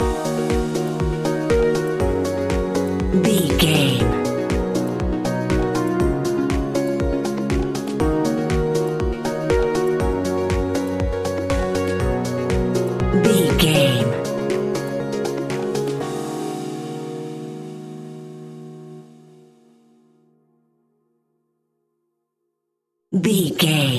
Aeolian/Minor
E♭
groovy
uplifting
hypnotic
dreamy
smooth
piano
drum machine
synthesiser
electro house
synth leads
synth bass